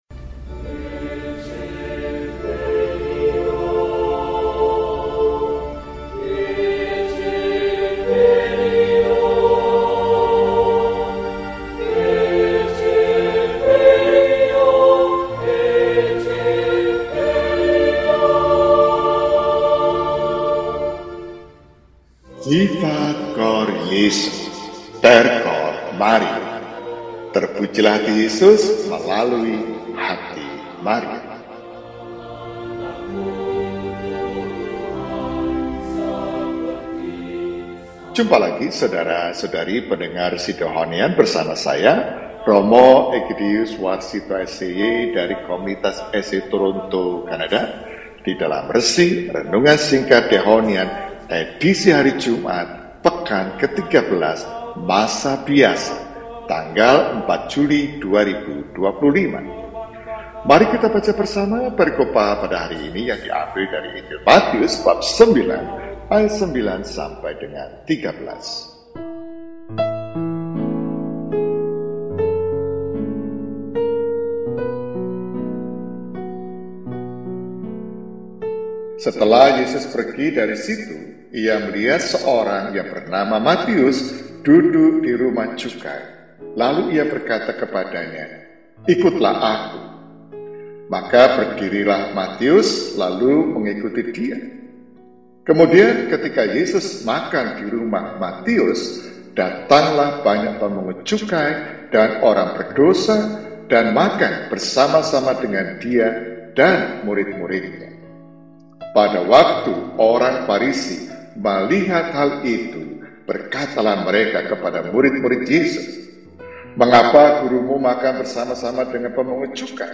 Jumat, 04 Juli 2025 – Hari Biasa Pekan XIII – RESI (Renungan Singkat) DEHONIAN